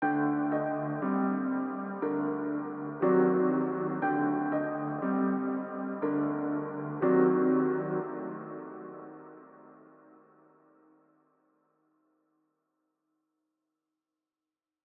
AV_Anxiety_120bpm_Gmin
AV_Anxiety_120bpm_Gmin.wav